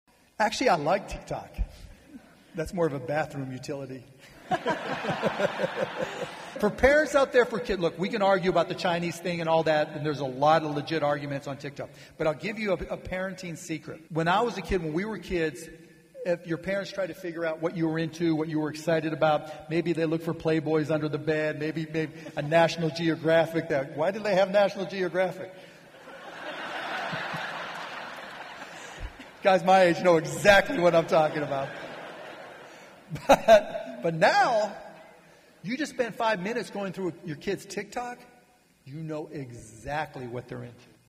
For instance, he talked about how his kids value branding and their social media accounts. He said he learns from them and how the next generation thinks. Hear more of Cuban's comments on parental oversight during his talk at the Dallas Regional Chamber's annual meeting in January.